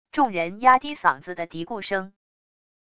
众人压低嗓子的嘀咕声wav音频